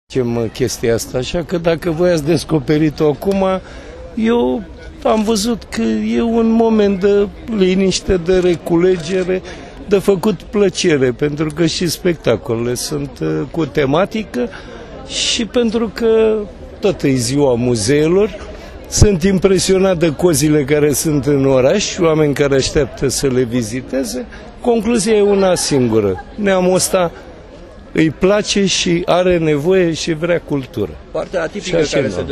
Primarul Capitalei Profesor Doctor Sorin Mircea Oprescu despre evenimentul din Bellu si despre Noaptea Muzeelor: